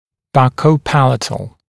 [ˌbʌkəu’pælətl][ˌбакоу’пэлэтл]щечно-небный